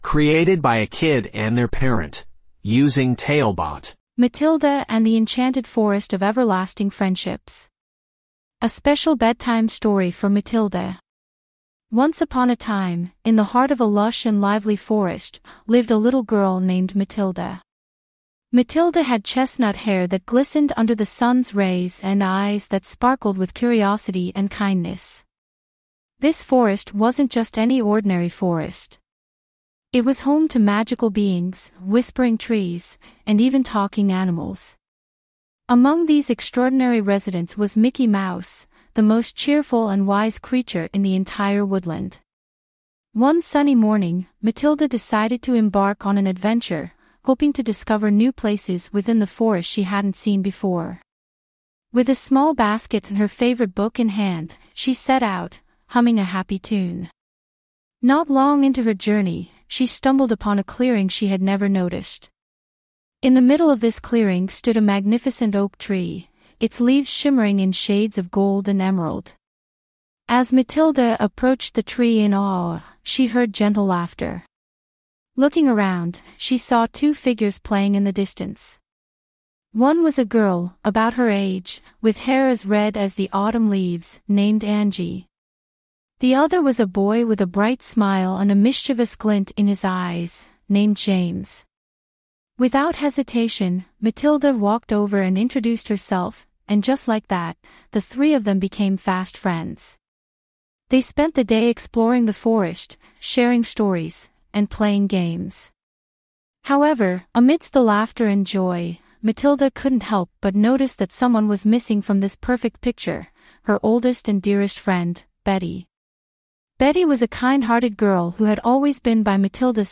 5 Minute Bedtime Stories
Write some basic info about the story, and get it written and narrated in under 5 minutes!